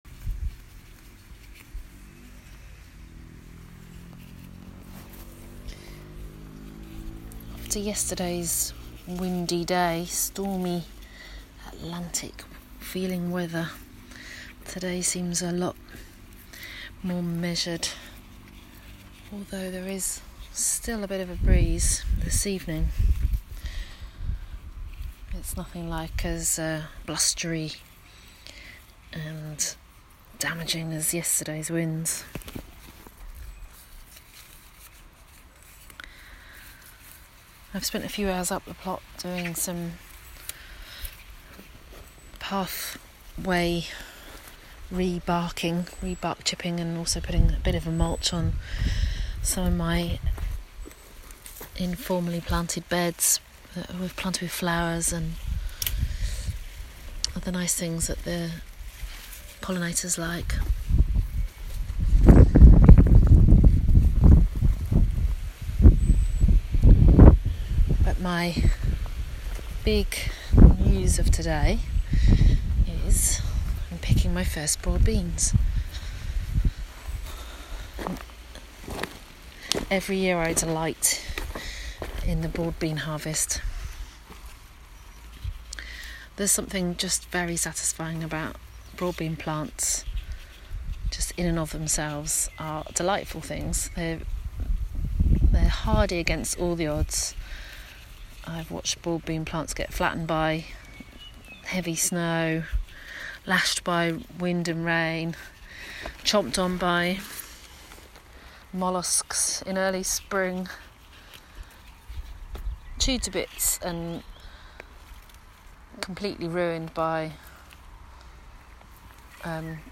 Hear from one woman and her dog as she explores the organic gardening world in short sound bites.
*Please note, outside recording carried out in accordance with national guidance as part of daily exercise with respect to social distancing*